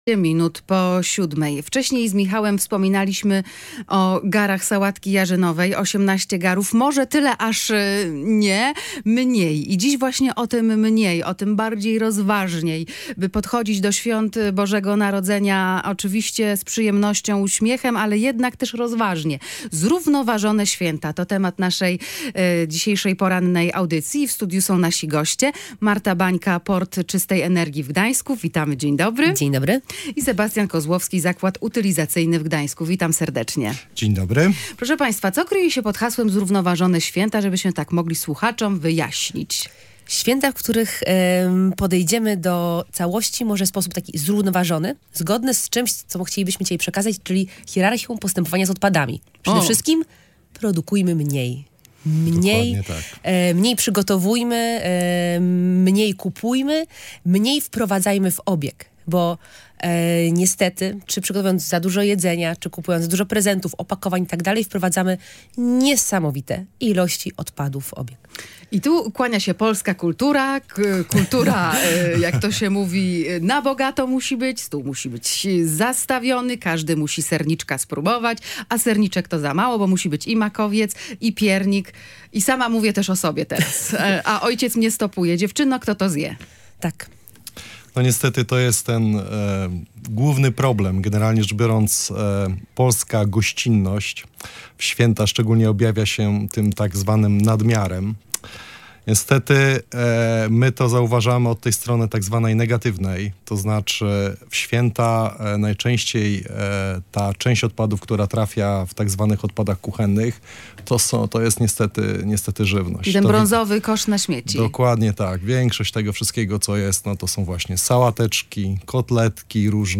Posłuchaj całej rozmowy o zrównoważonych świętach